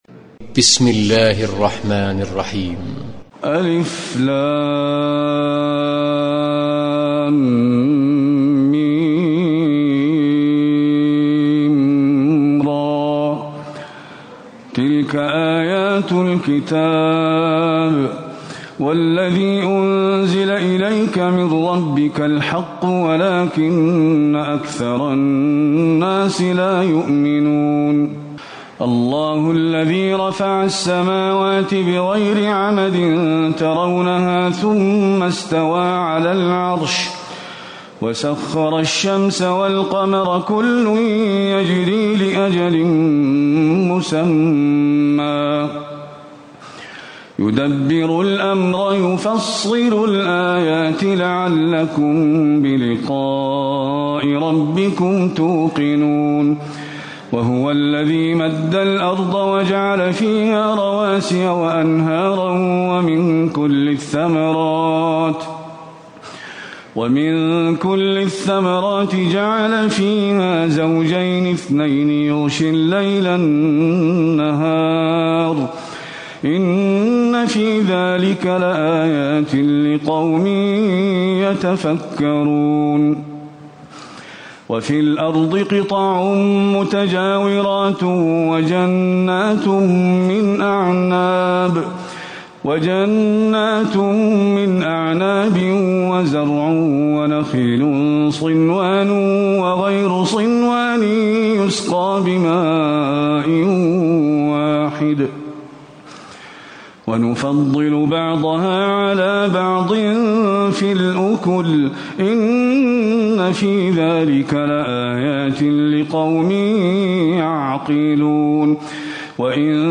تراويح الليلة الثانية عشر رمضان 1437هـ سورتي الرعد (1-43) و إبراهيم (1-52) Taraweeh 12 st night Ramadan 1437H from Surah Ar-Ra'd and Ibrahim > تراويح الحرم النبوي عام 1437 🕌 > التراويح - تلاوات الحرمين